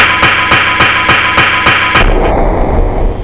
drums.mp3